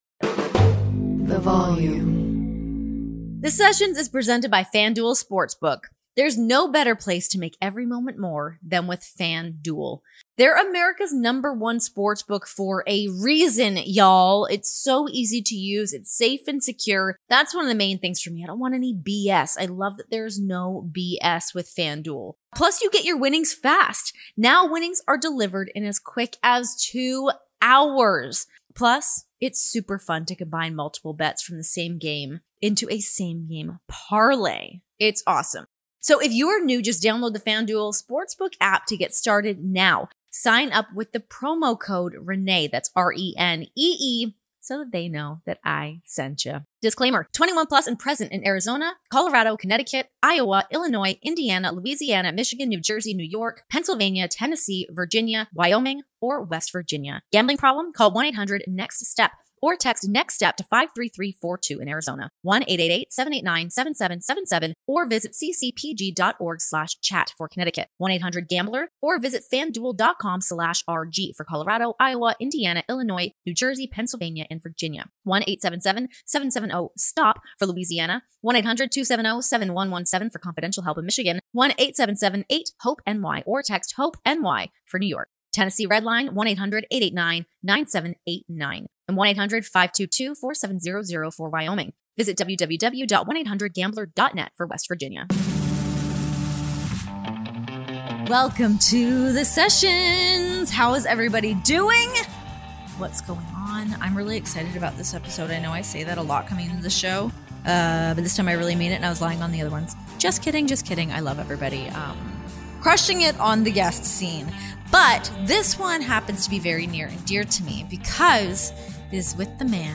Dustin Rhodes joins The Sessions for one of our most emotional interviews yet, where we discuss his longevity in wrestling, his moment of clarity after years of drug and alcohol abuse, whe...